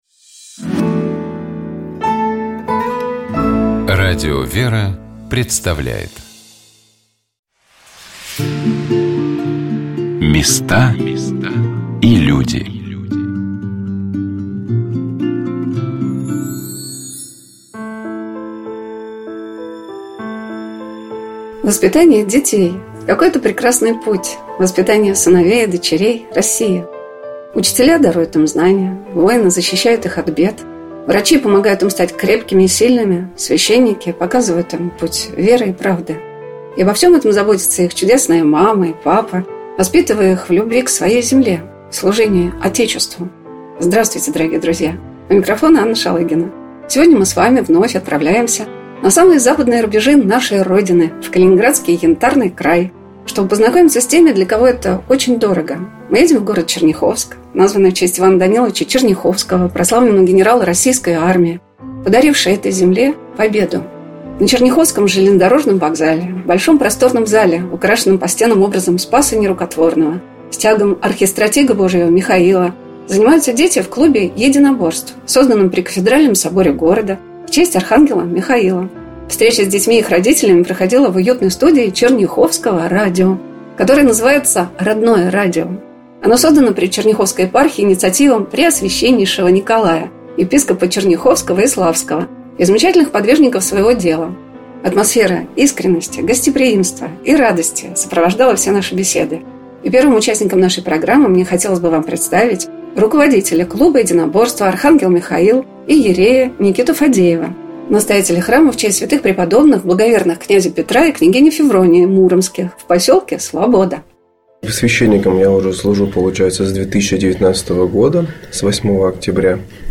С этими людьми и общаются наши корреспонденты в программе «Места и люди». Отдаленный монастырь или школа в соседнем дворе – мы открываем двери, а наши собеседники делятся с нами опытом своей жизни.